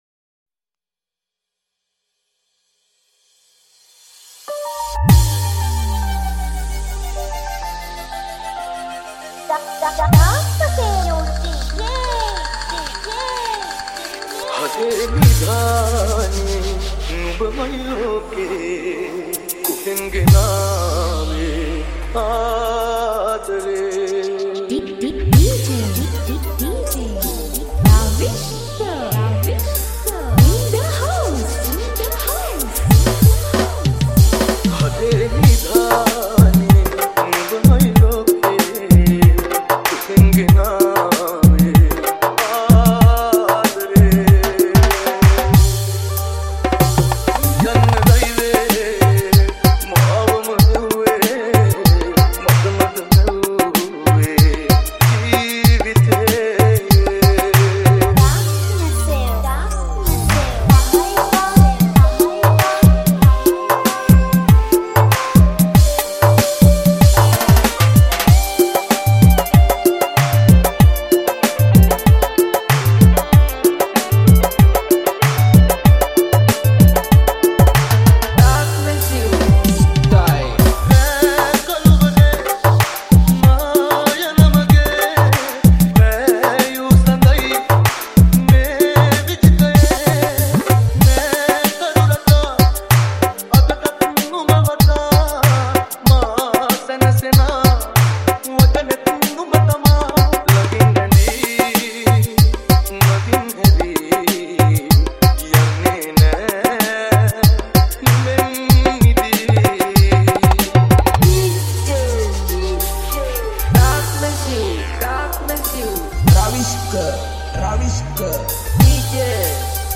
4-4 Live Bend Style Remix